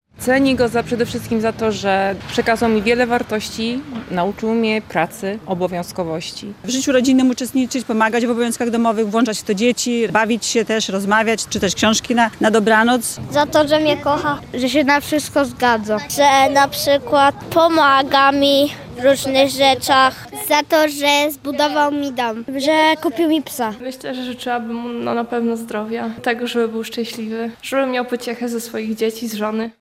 Sonda: Czego mieszkańcy Łomży życzą swoim ojcom?
Mieszkańcy Łomży opowiedzieli. za co cenią swoich ojców oraz czego życzą im w tym szczególnym dniu.